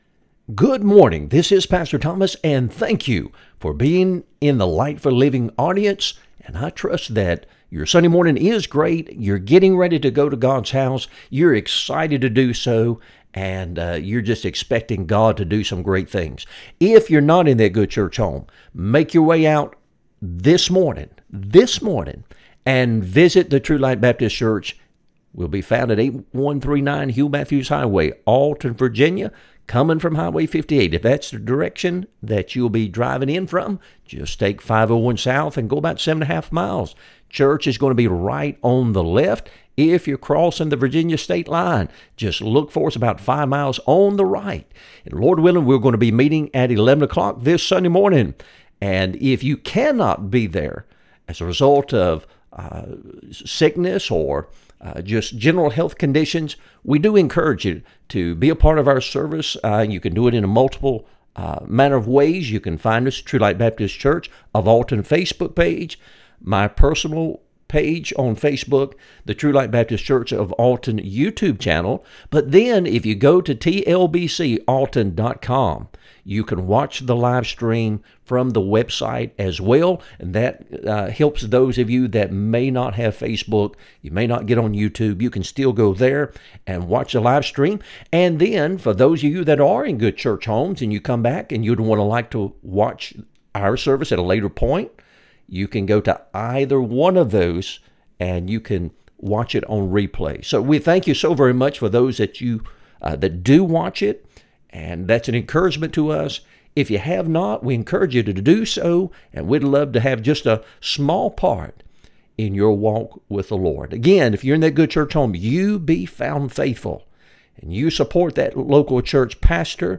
Sermons | True Light Baptist Church of Alton, Virginia